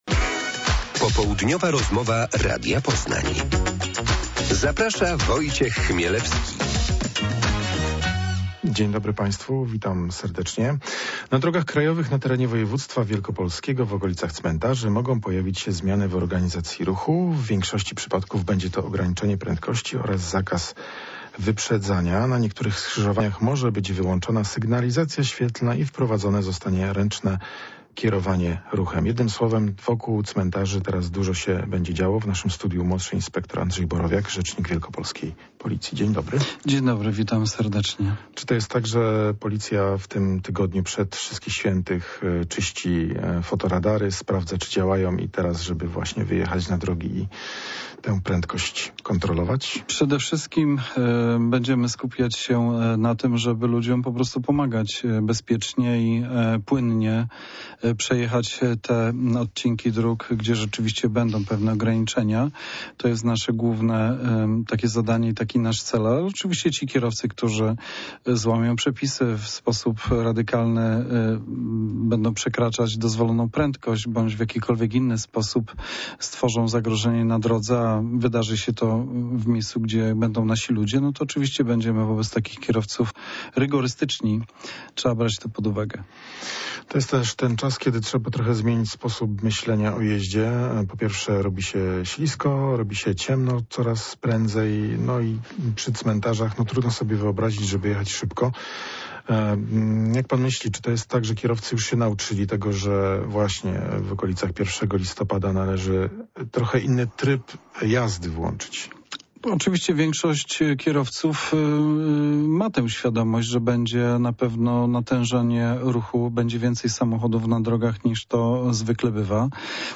Popołudniowa rozmowa Radia Poznań – Bezpieczeństwo we Wszystkich Świętych